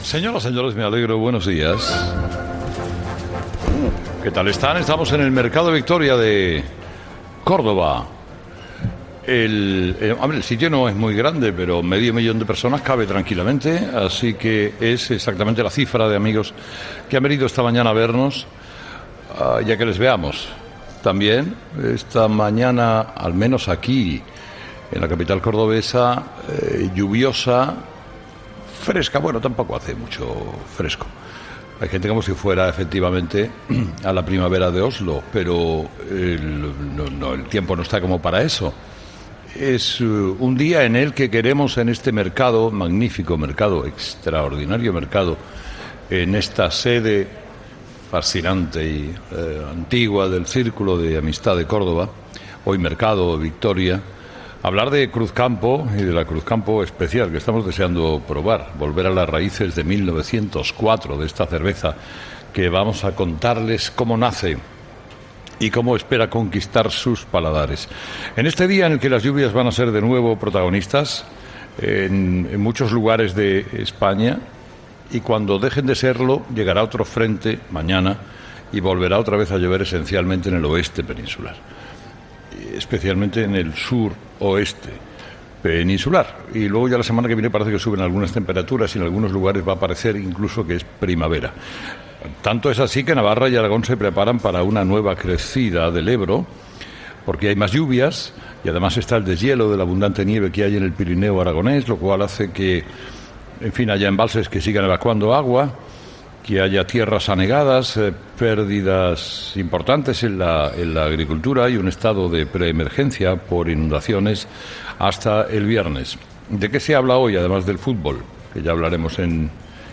Estamos en el Mercado Victoria de Córdoba.